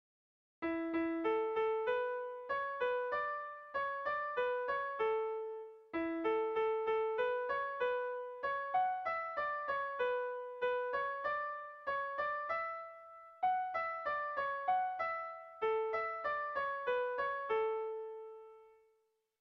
Irrizkoa
A1A2B